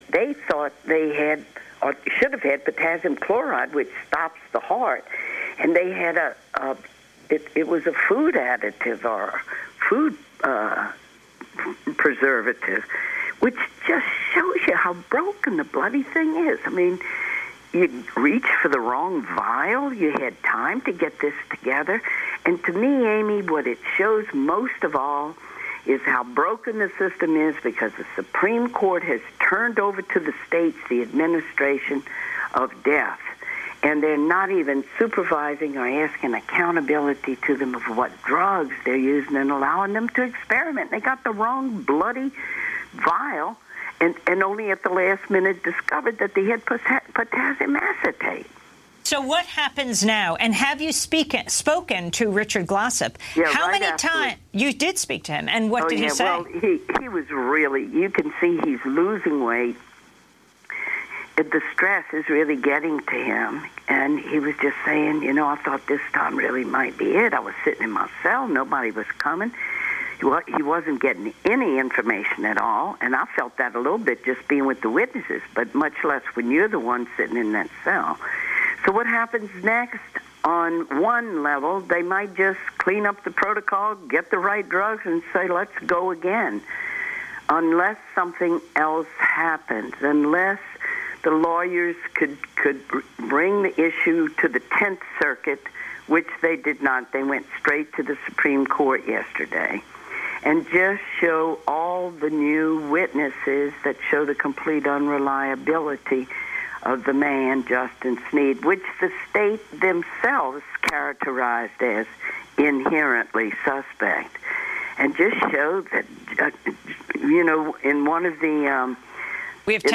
Progressive talk radio from a grassroots perspective